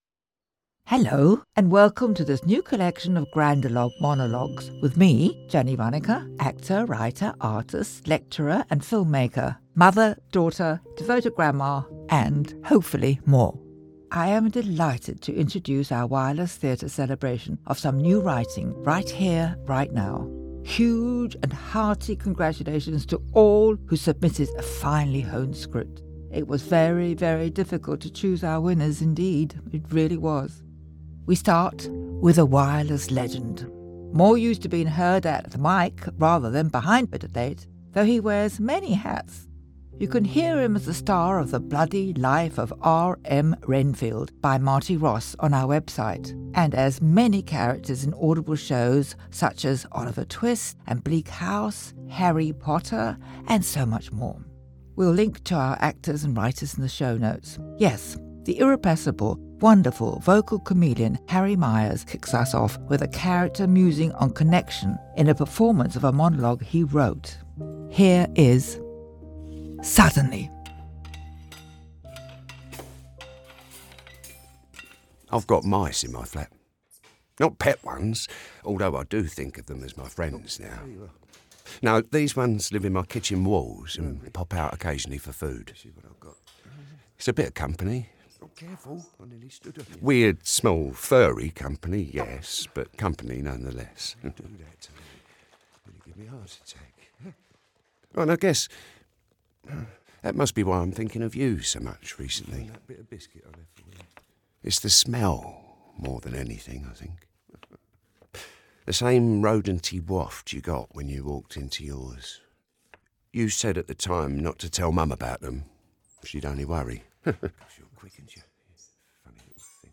Wireless Theatre Ltd Audio Drama Grandologues Feb 14 2026 | 00:40:30 1x 00:00 / 00:40:30 Subscribe Share Spotify RSS Feed Share Link Embed